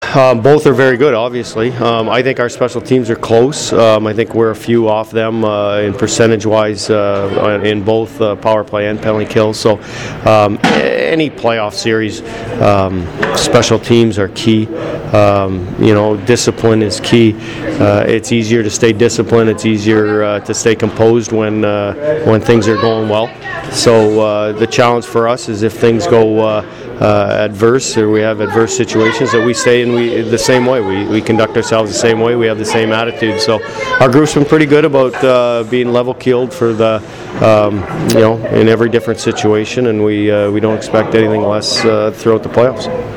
I attended practice and talked with plenty of guys on the team.